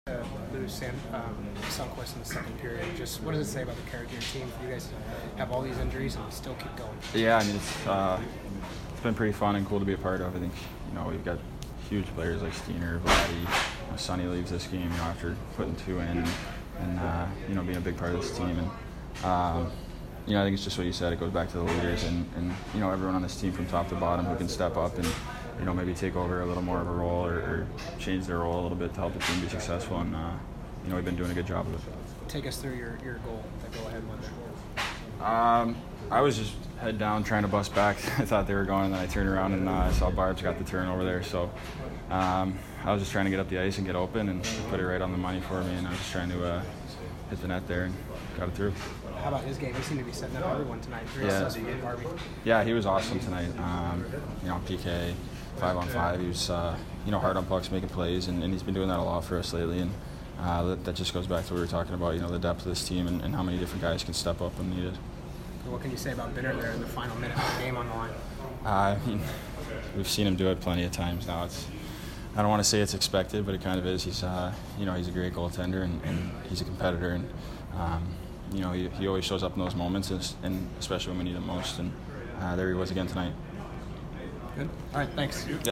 Sanford post-game 11/27